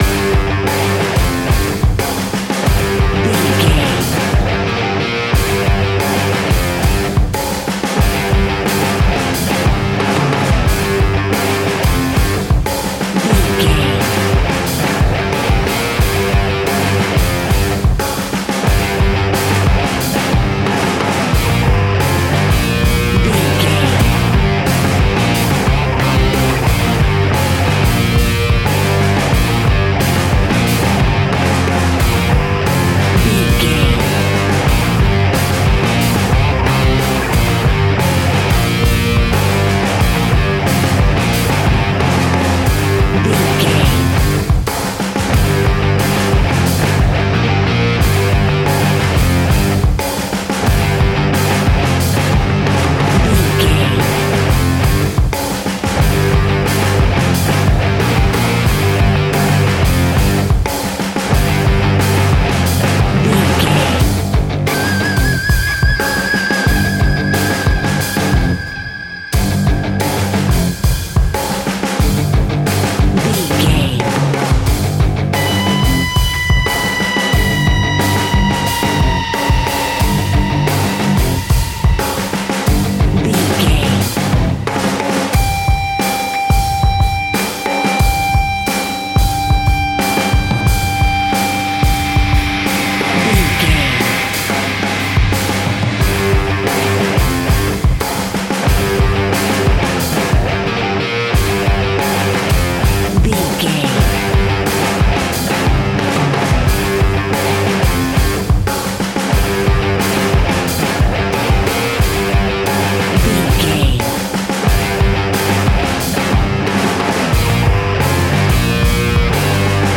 Ionian/Major
hard rock
heavy rock
distortion